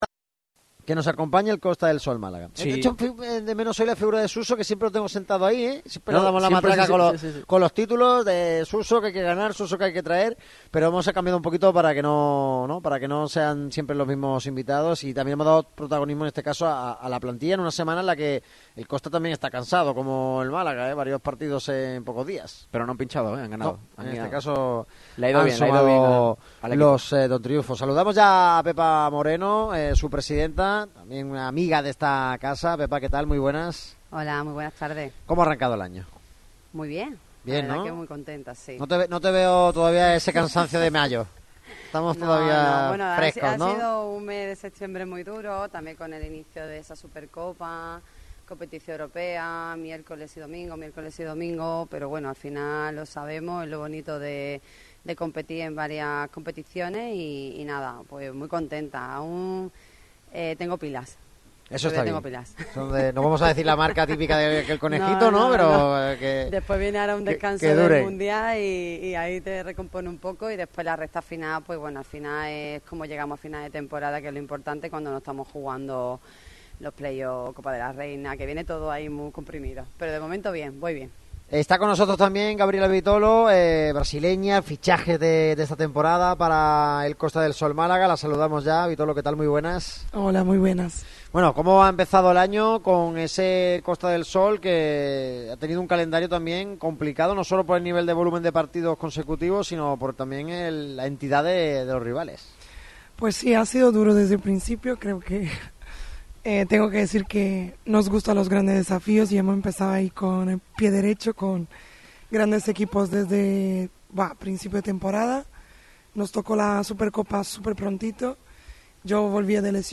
Programa especial del micrófono rojo en la Fábrica de Cervezas Victoria.